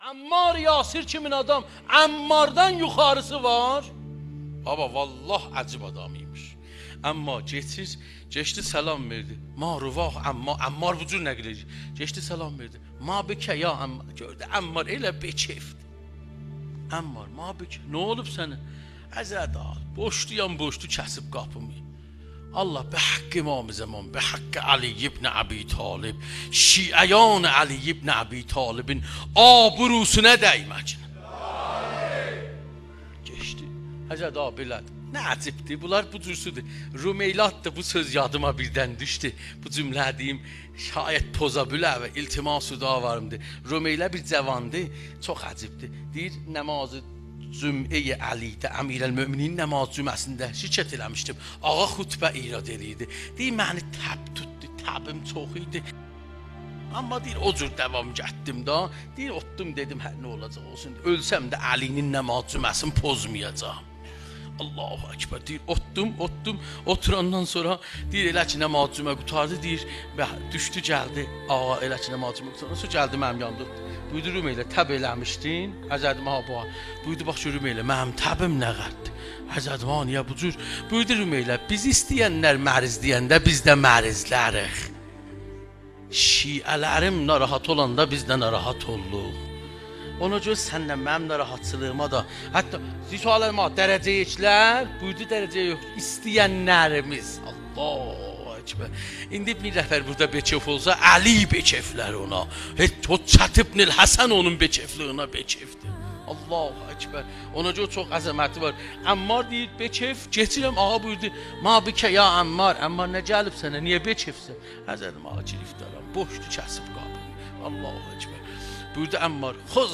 متن قطعه : سخنرانی